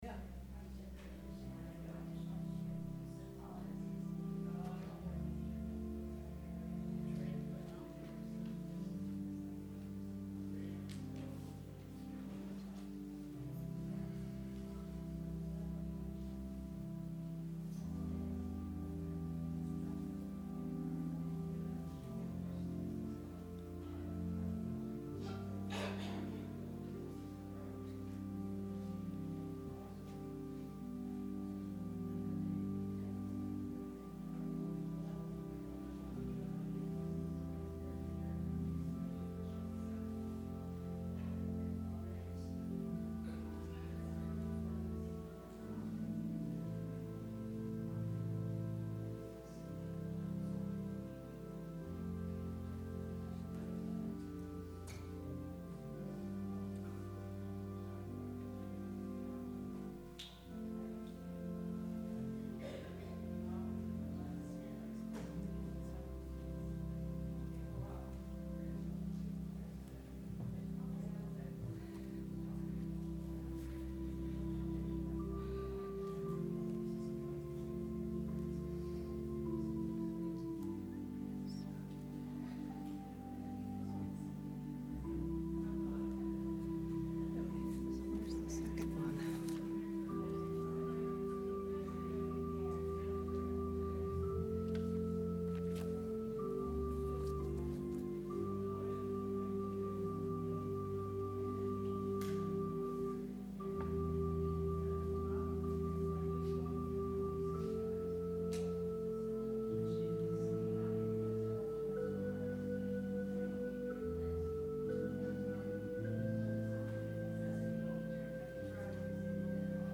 advent-sermon-may-5-2019.mp3